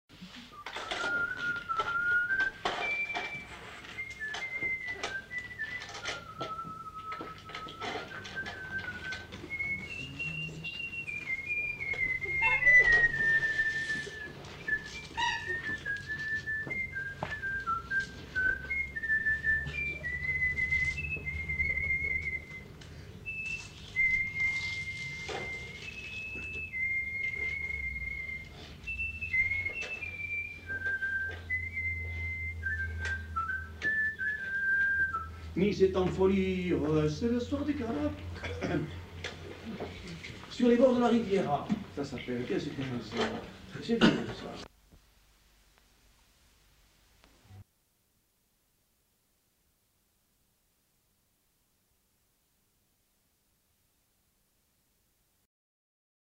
Aire culturelle : Agenais
Lieu : Foulayronnes
Genre : chant
Effectif : 1
Type de voix : voix d'homme
Production du son : sifflé